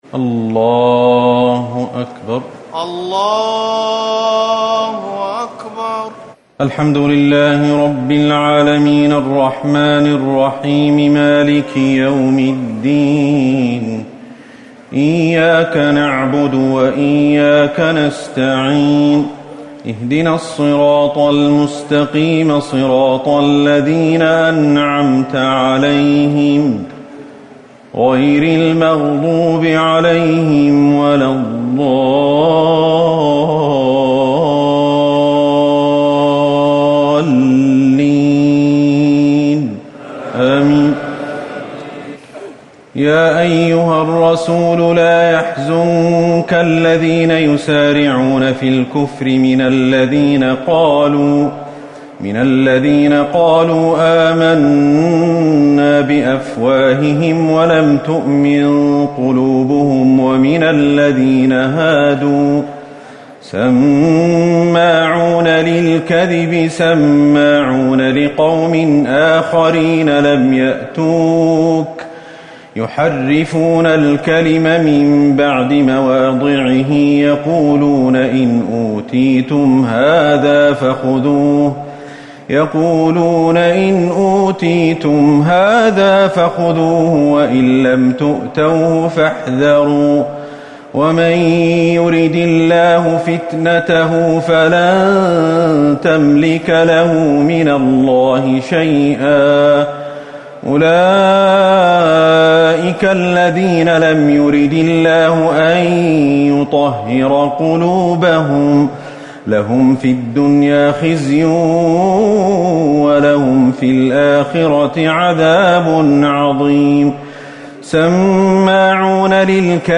ليلة ٦ رمضان ١٤٤٠ من سورة المائدة ٤١-١٠٨ > تراويح الحرم النبوي عام 1440 🕌 > التراويح - تلاوات الحرمين